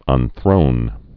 (ŭn-thrōn)